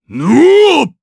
Bernheim-Vox_Attack4_jp.wav